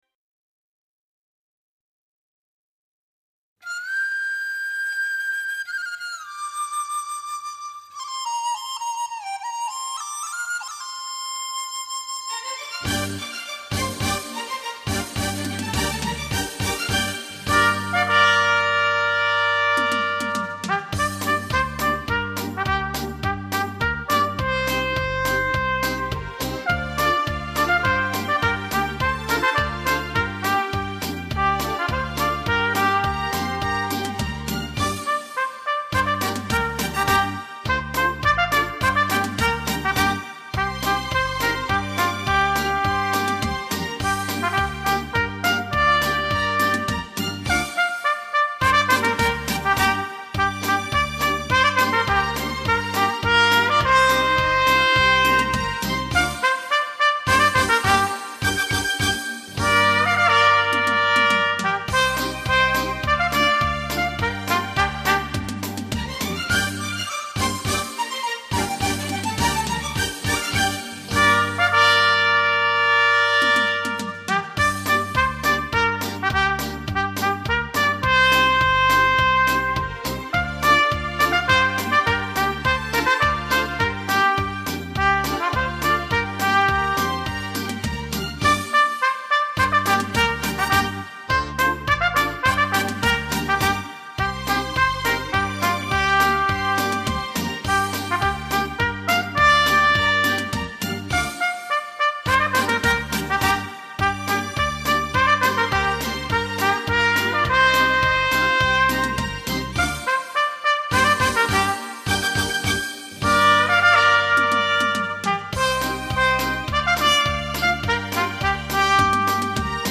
时而嘹亮激昂，时而又如